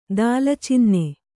♪ dālacinne